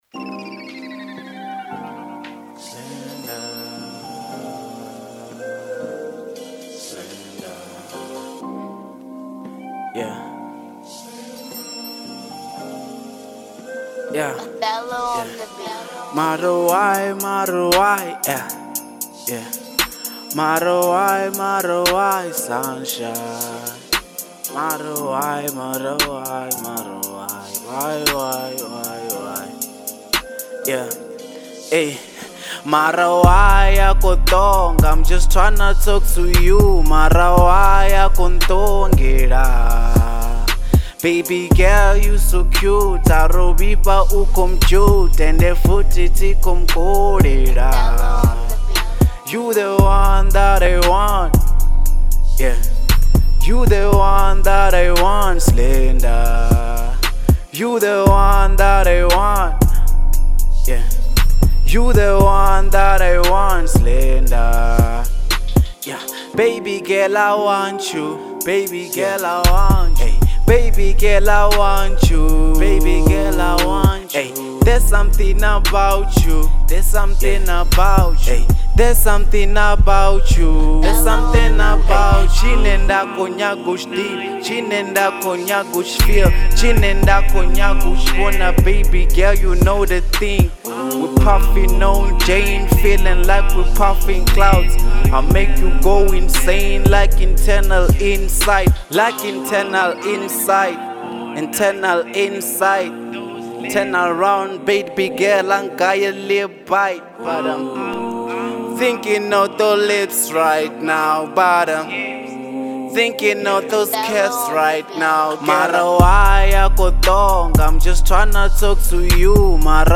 02:46 Genre : Venrap Size